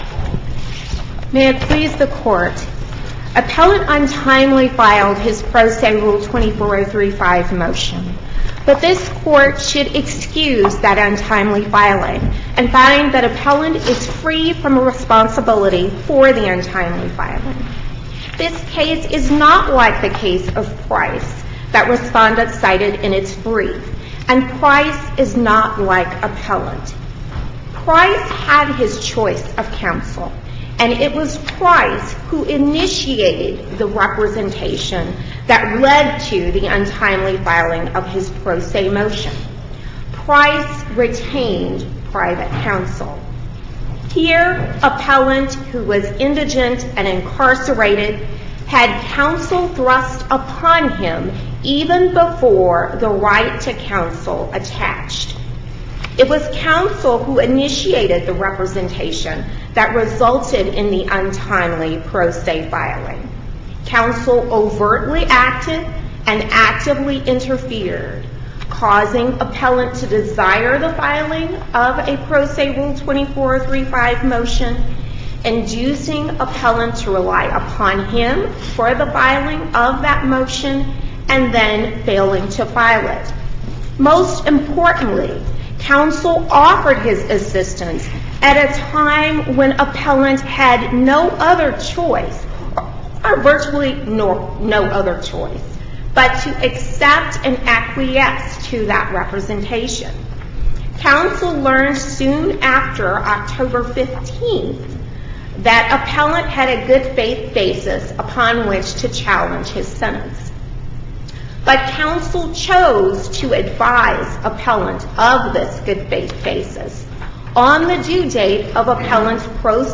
MP3 audio file of arguments in SC96032